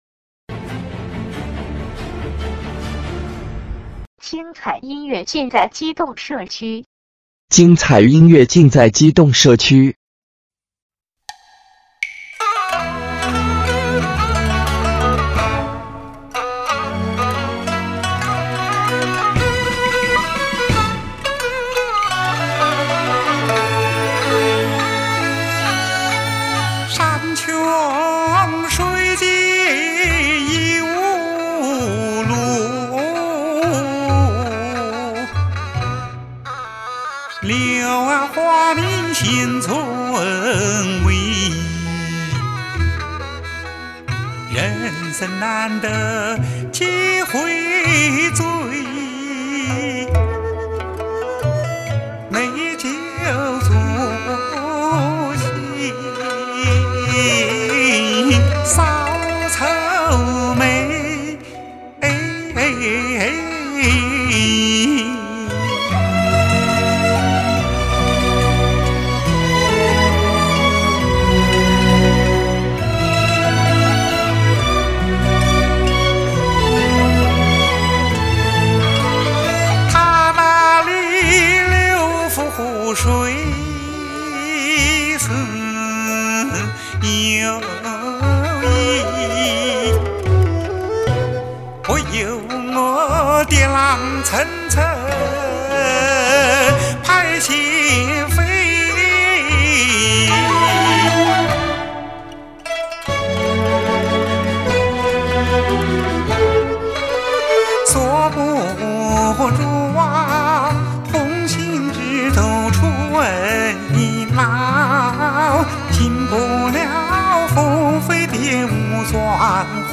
选段